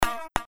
Gemafreie Sounds: Bewegung
mf_SE-132-flying_tone_2.mp3